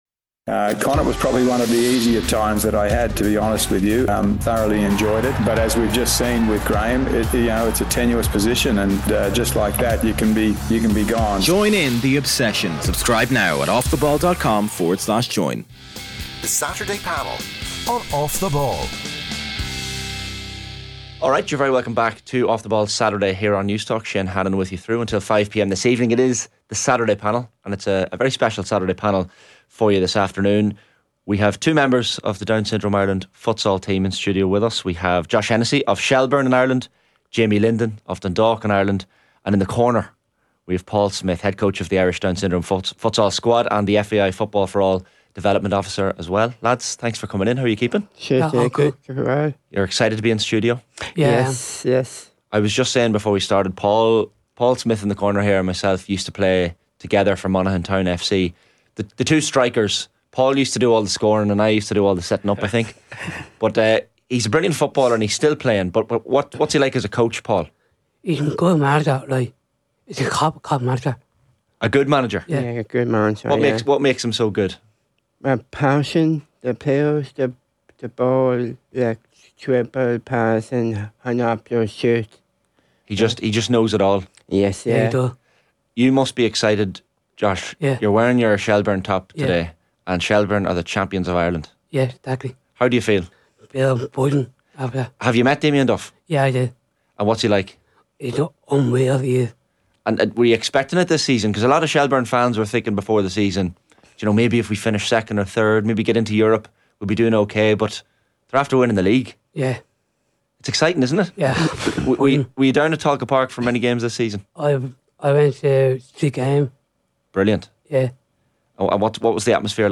Live on Off The Ball from 1pm every Saturday, or subscribe to get the podcast!